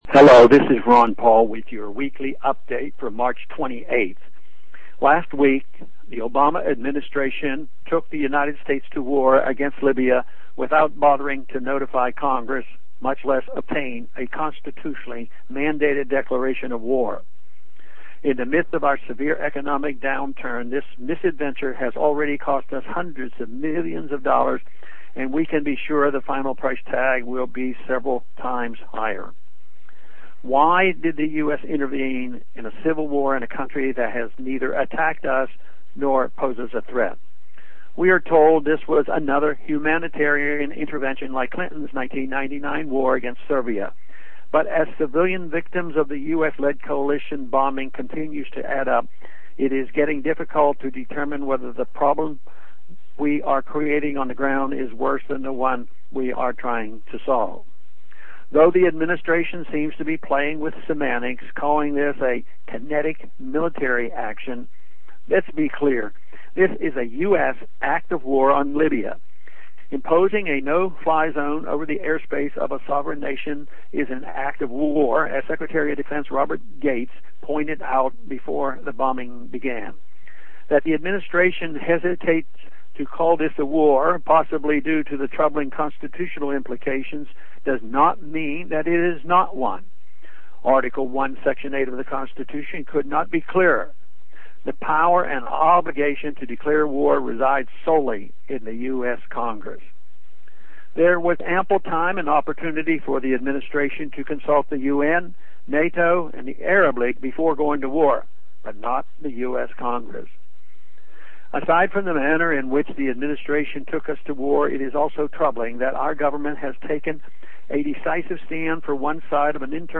To hear Rep. Ron Paul deliver this address,